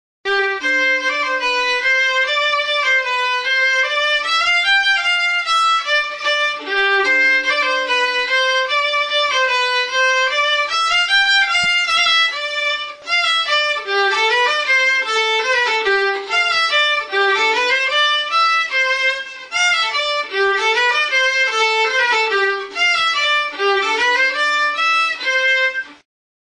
BIOLINA | Soinuenea Herri Musikaren Txokoa
Biolin arrunta da (4/4). Igurtzitzeko arkua dauka.